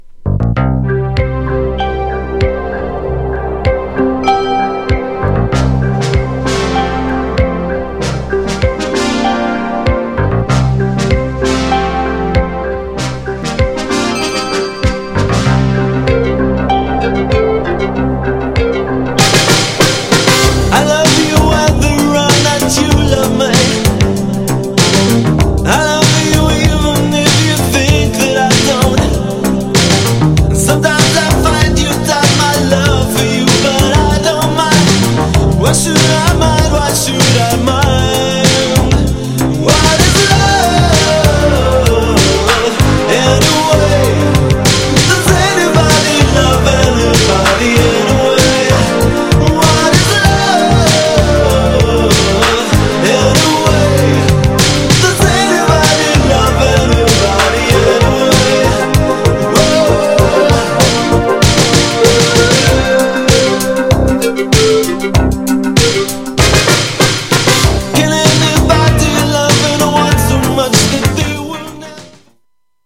アメリカでもヒットしたキャッチーなサビのコーラスが印象的な80's TUNE!!
GENRE Dance Classic
BPM 106〜110BPM
# アーバン # キャッチー # ニューウェーブ # ハイエナジー